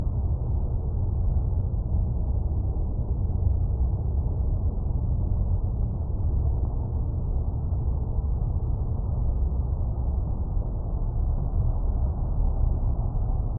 I applied a harsh equalization, cutting all frequencies below 80 Hz and above,
the mid frequencies, and, thus, the sound becomes something completely
Recording 2 (after EQ)
warmth and presence.